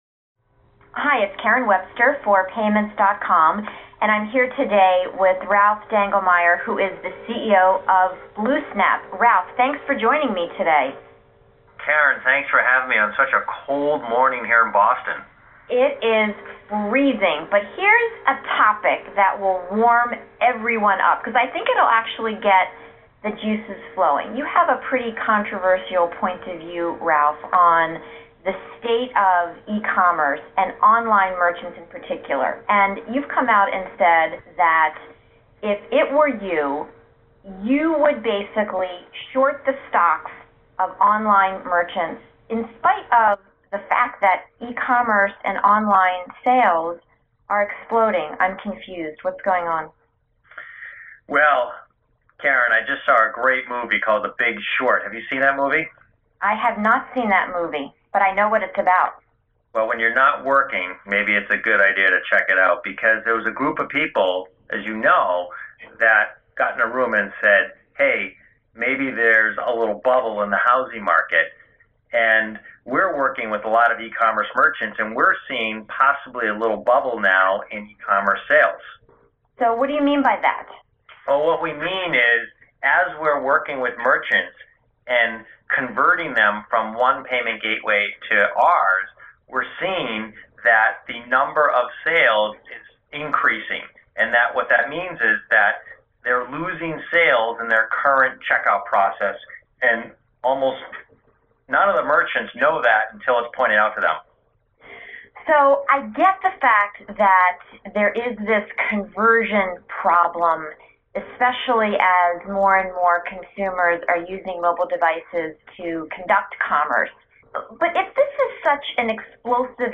BlueSnap-Podcast.mp3